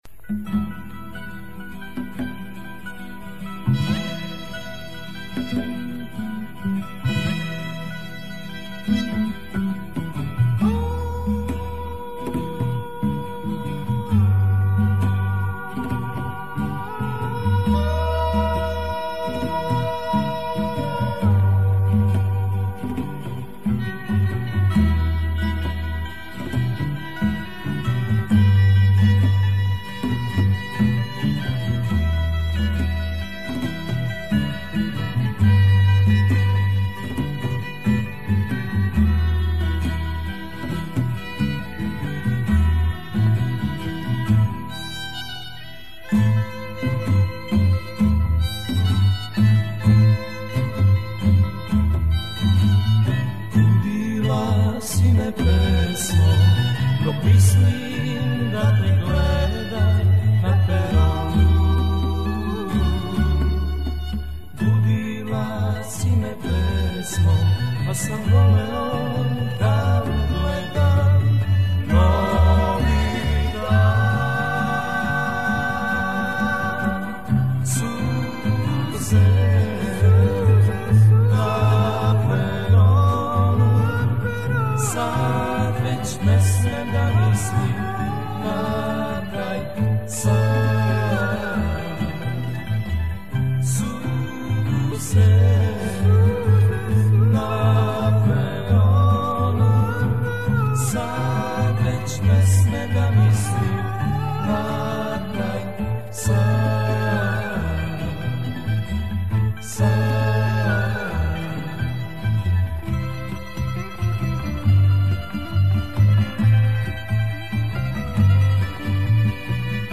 Записал когда-то с 45-ки югославской.